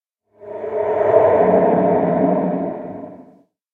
Minecraft Version Minecraft Version snapshot Latest Release | Latest Snapshot snapshot / assets / minecraft / sounds / ambient / cave / cave13.ogg Compare With Compare With Latest Release | Latest Snapshot
cave13.ogg